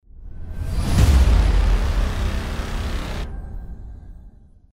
На этой странице собраны звуки внезапного появления: резкие переходы, неожиданные всплески, тревожные сигналы.